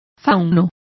Complete with pronunciation of the translation of faun.